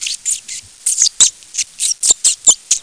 00131_Sound_marmotte.mp3